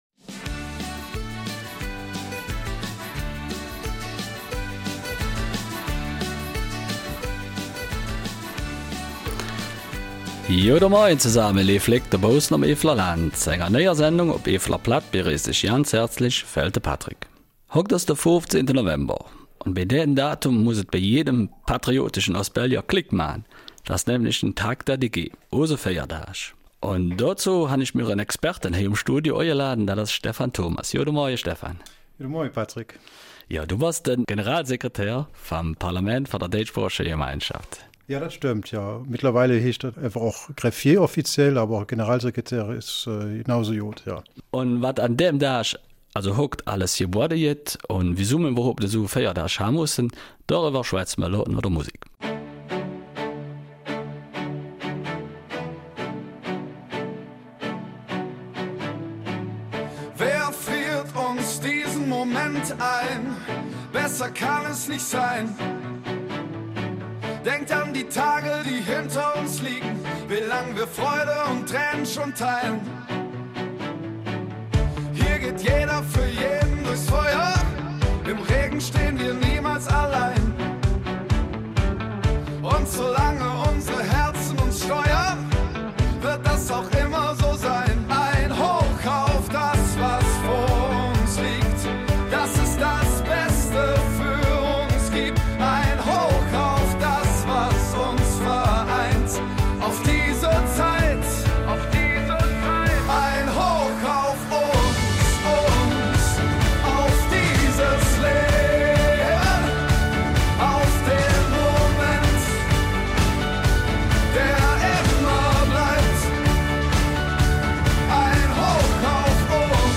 Eifeler Mundart zum Tag der DG